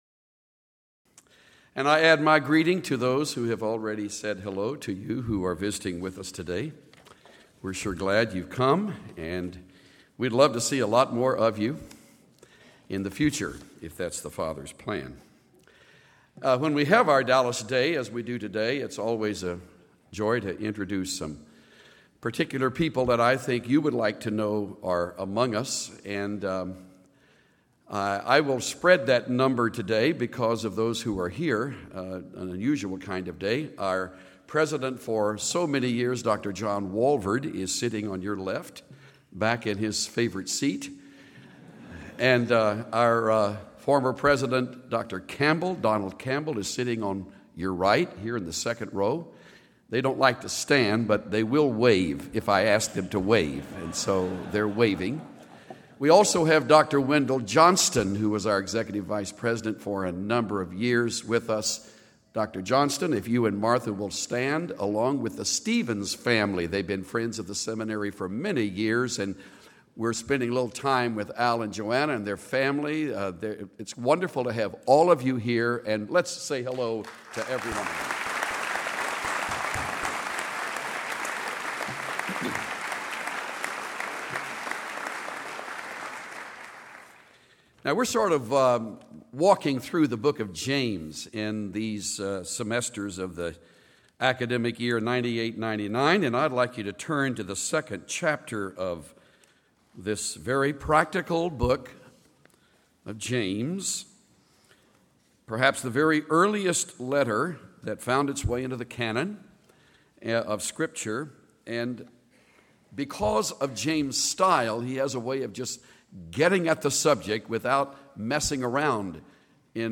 Chuck Swindoll encourages the audience not to be prejudiced or partial to anyone, contrasting it against how we are to treat one another according to James 2.
Closing Prayer